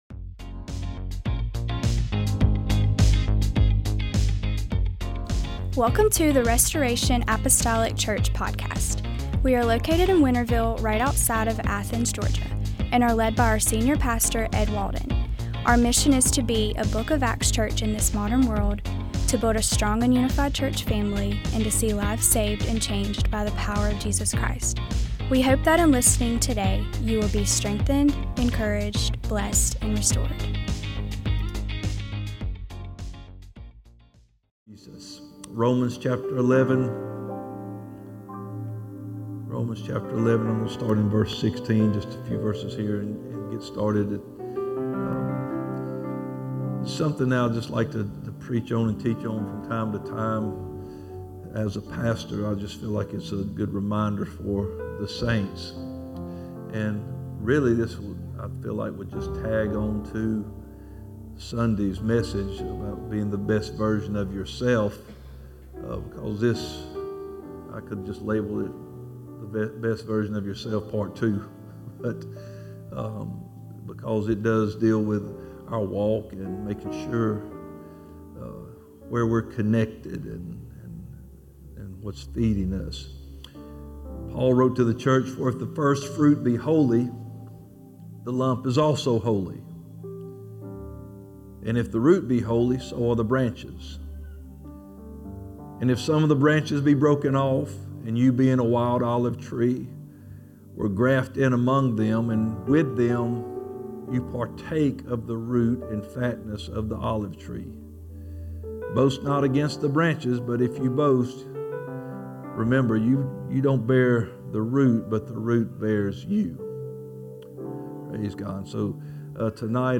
the root bears us" In this sermon, pastor talks about how we must stay rooted in Jesus.